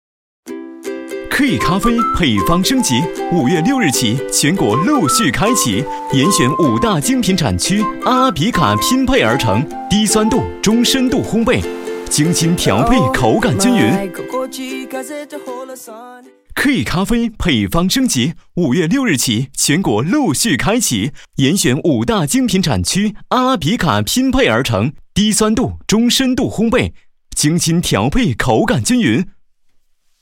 Programas de TV
Clean audio with no breaths or mouth noises
Sennheiser MKH 416 Mic, UA Volt 276 Interface, Pro Recording Booth, Reaper
BarítonoBajo